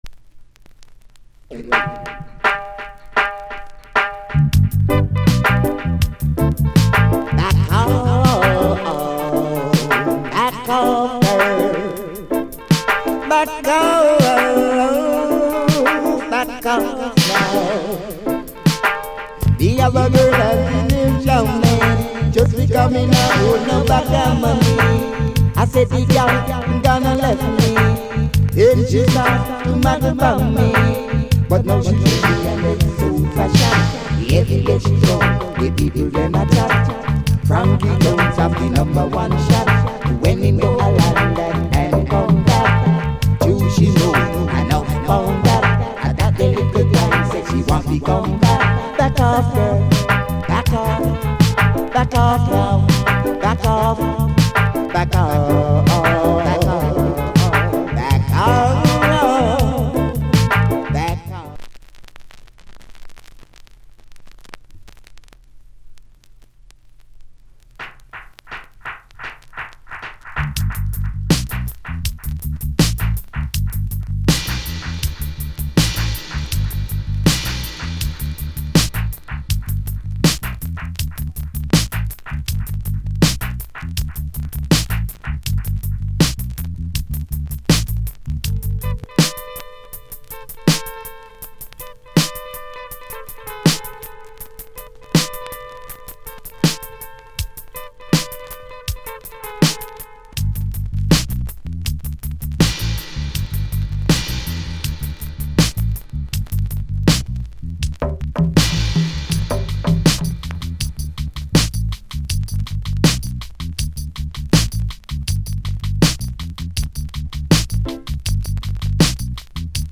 * '83 Good vocal